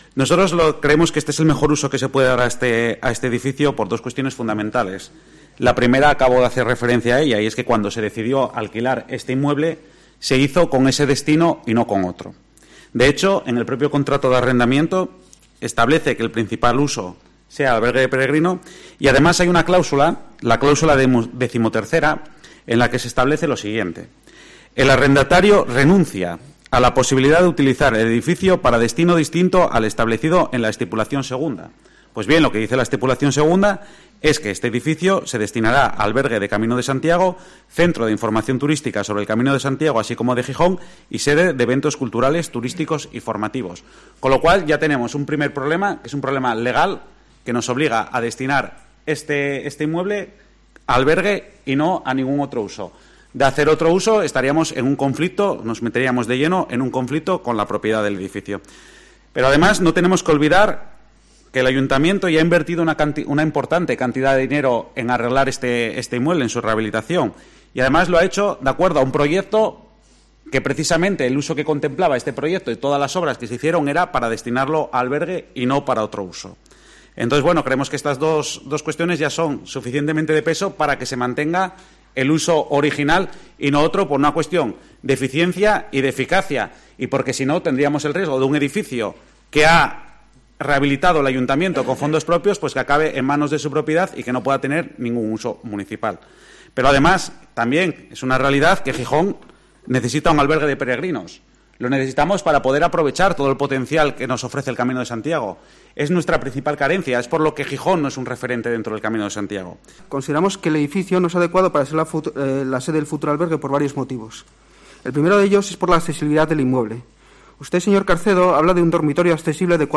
Rubén Pérez (Ciudadanos) y Santos Tejón (PSOE) hablan de los usos de la Casa Paquet